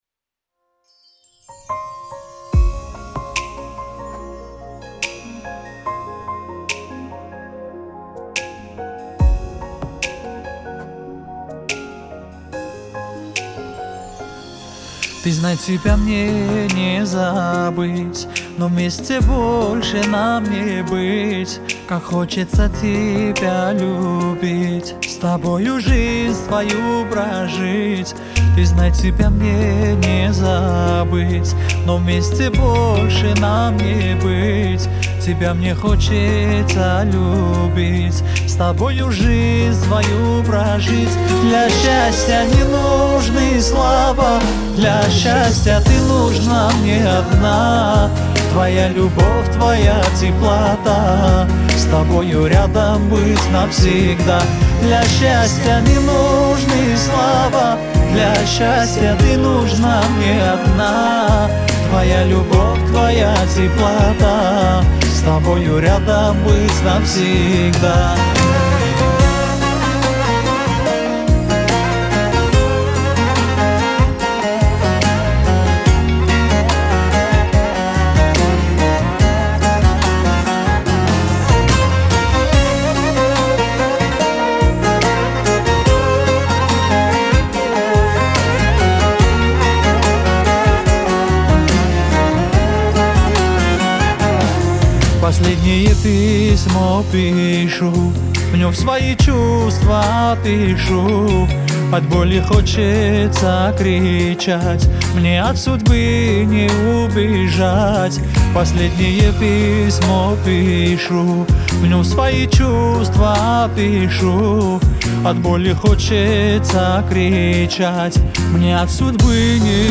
Трек размещён в разделе Русские песни / Армянские песни.